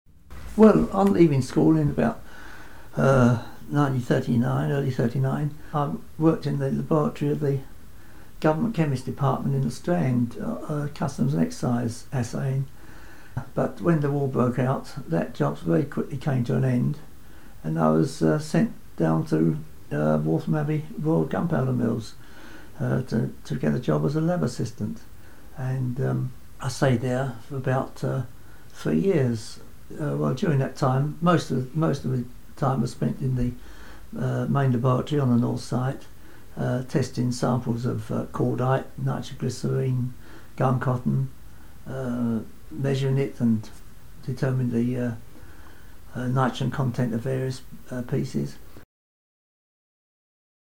WAOH - Waltham Abbey Oral History